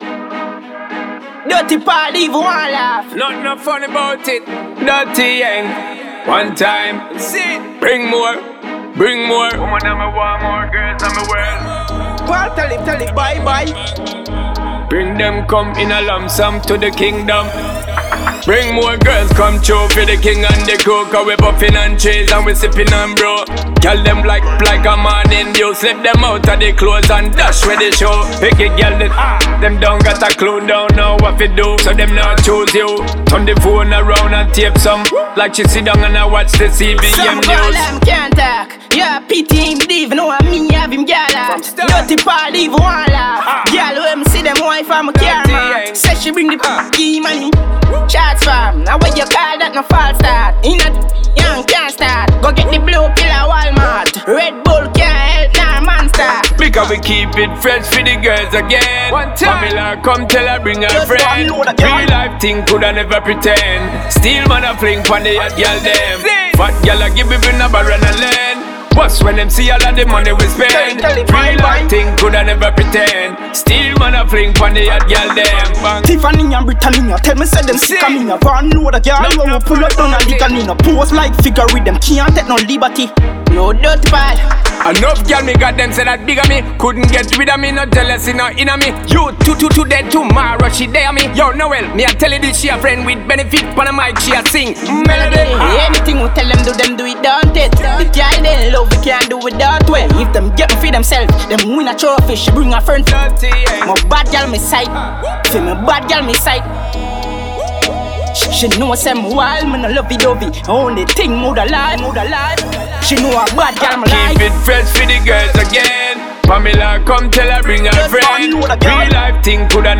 энергичная регги-танцевальная композиция